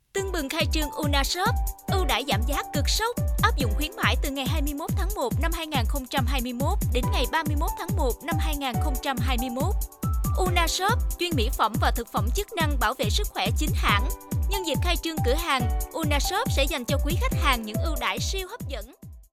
Thu âm phát loa cửa hàng